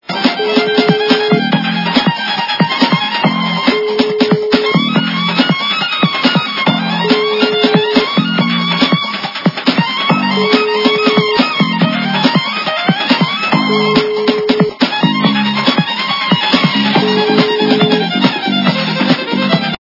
классика